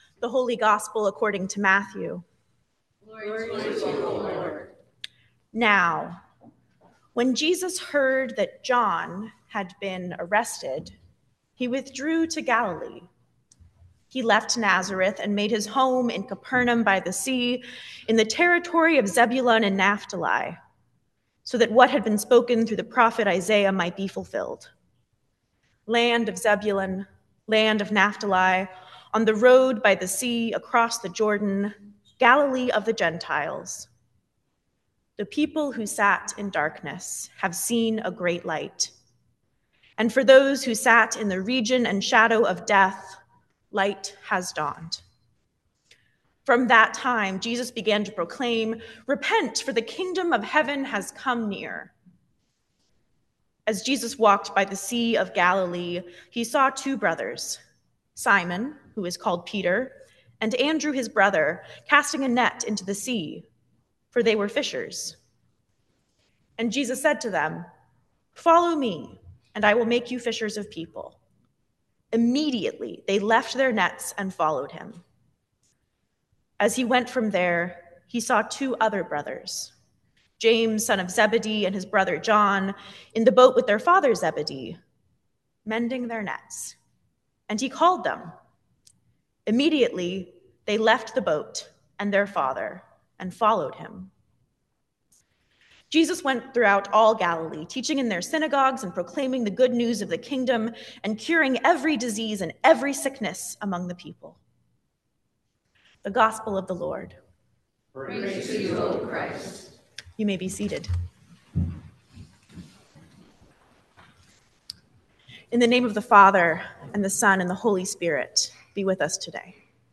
Sermon for Reconciling in Christ Sunday 2026